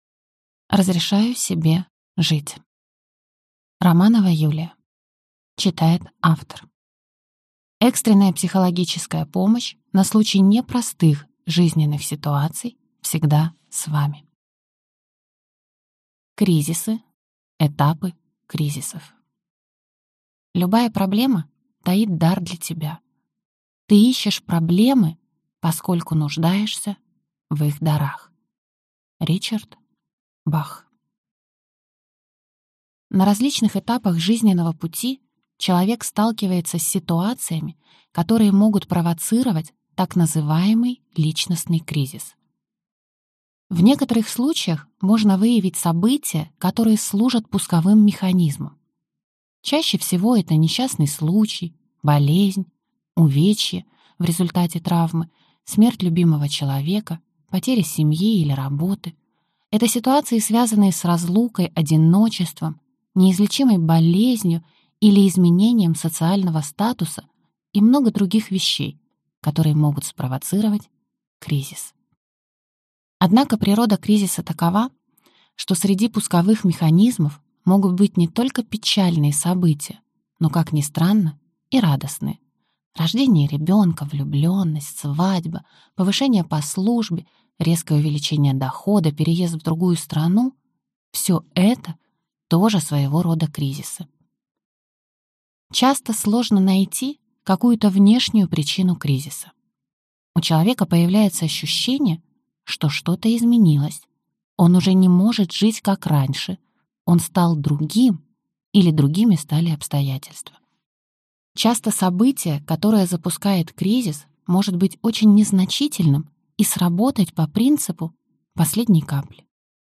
Аудиокнига Разрешаю себе жить | Библиотека аудиокниг